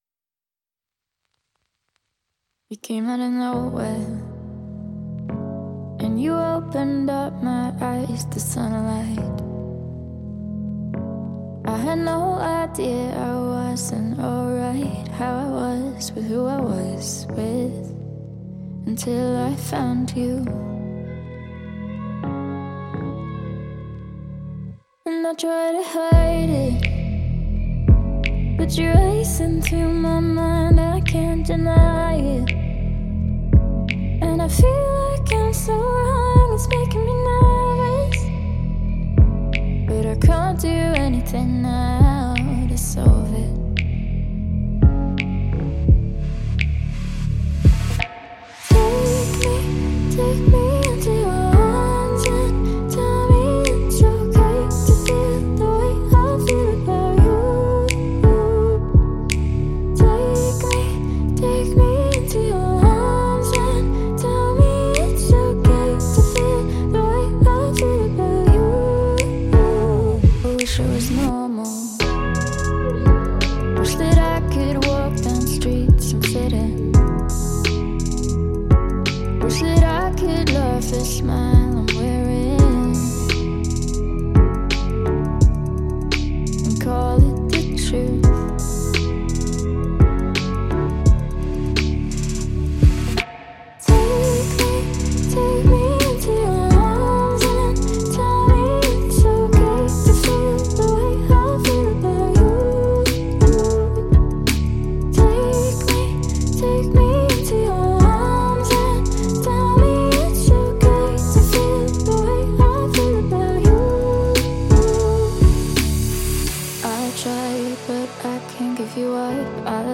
# Pop